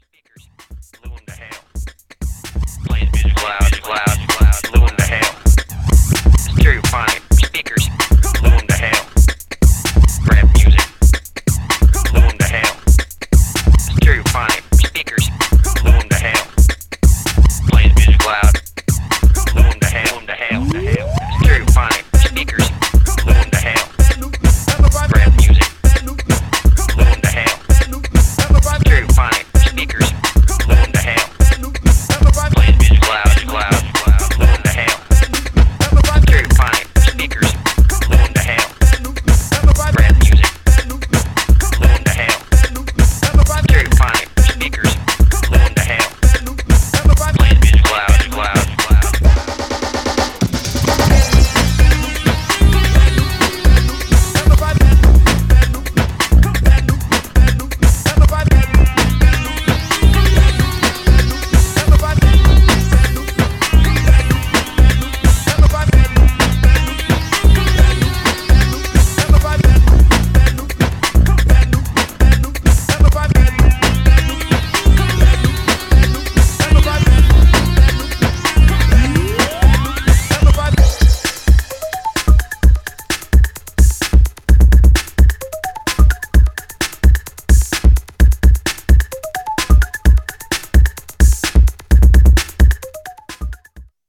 Styl: Electro, Breaks/Breakbeat